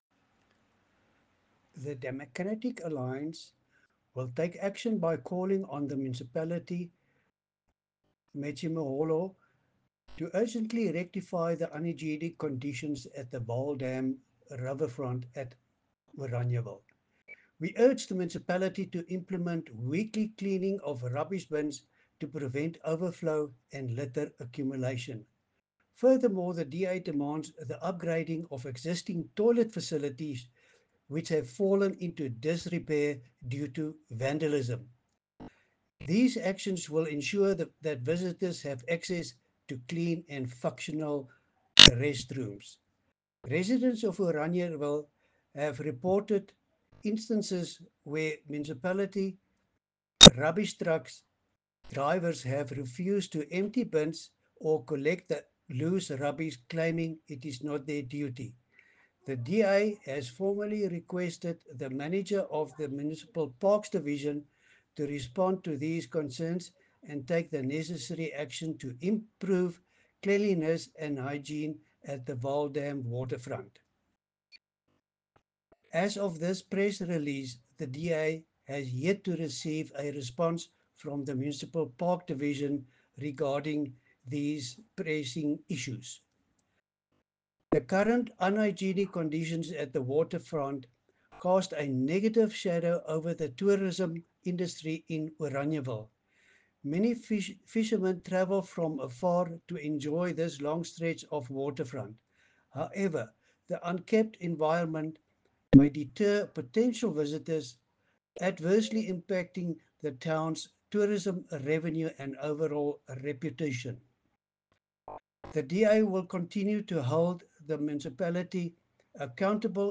Afrikaans soundbites by Cllr Louis van Heerden and